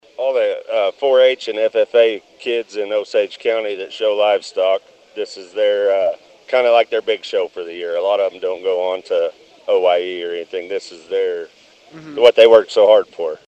KRIG 104.9 FM reported live from the event on Thursday morning.